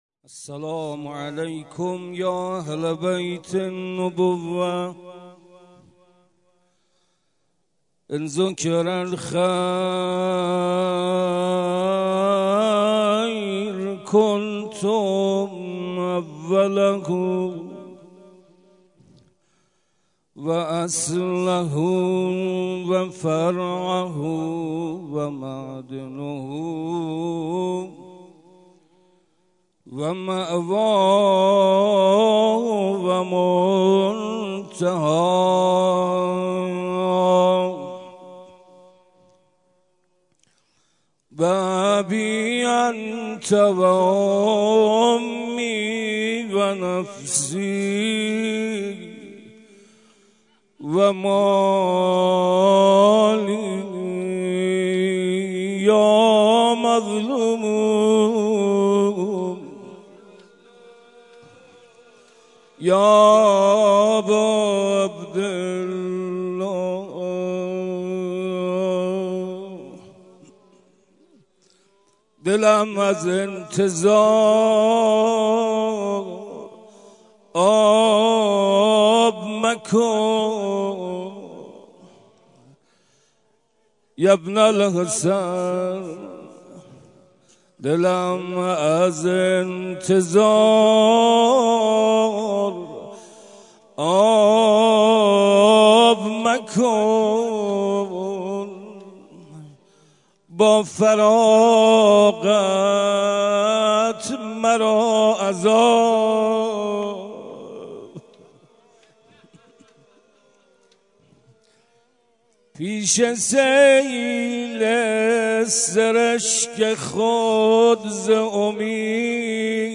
شب هفدهم صفر در حسینیه مرحوم چمنی